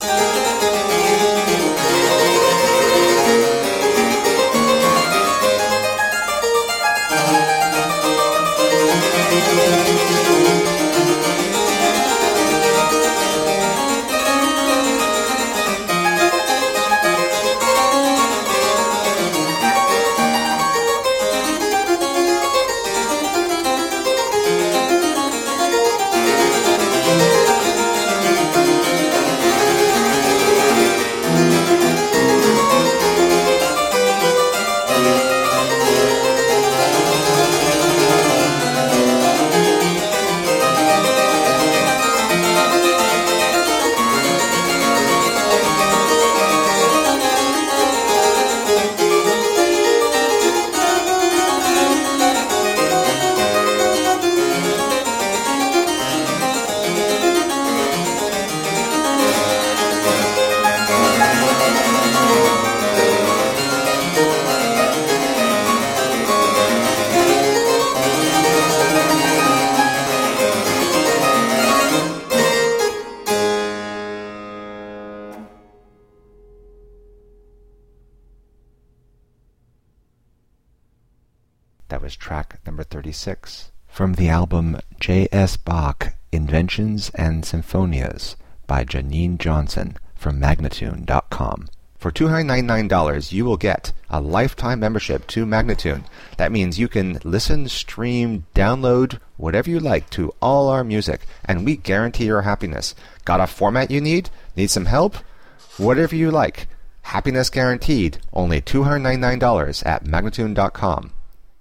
Classical, Baroque, Instrumental
Harpsichord